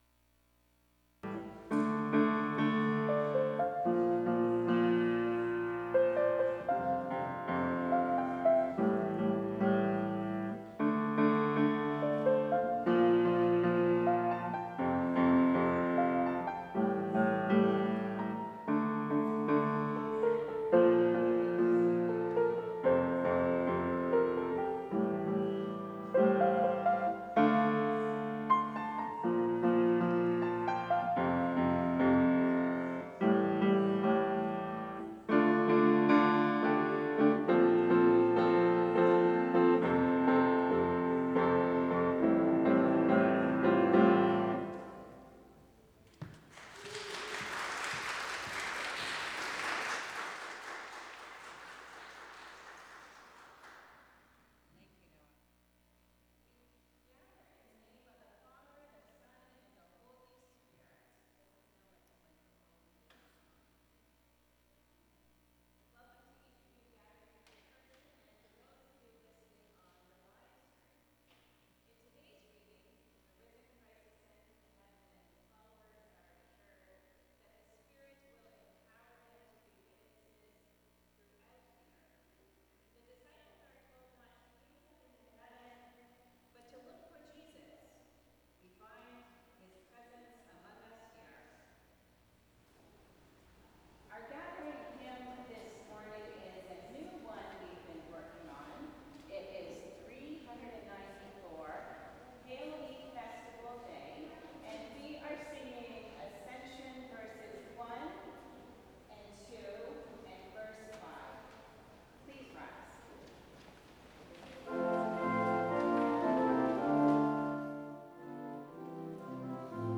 Worship Service Sunday June 1, 2025